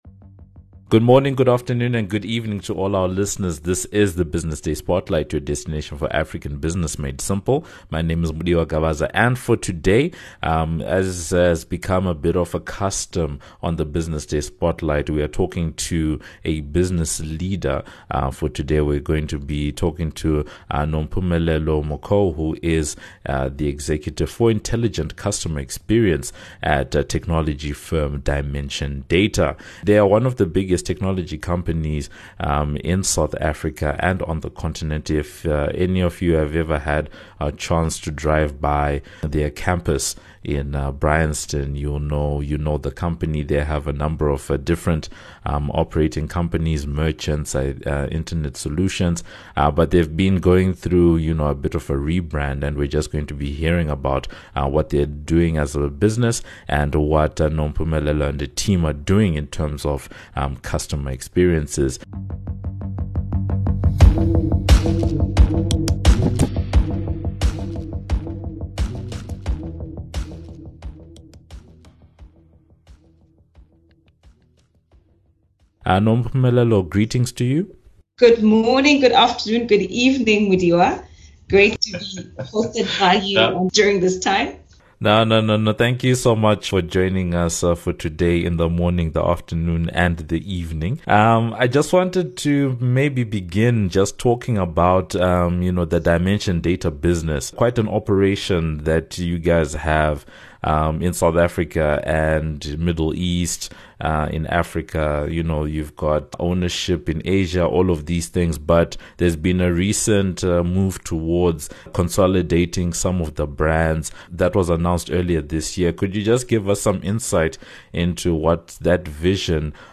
In this edition of the Business Day Spotlight, we’re talking to a business leader about the work being done at one of the country’s largest technology firms, and the experience of being a woman in that sector.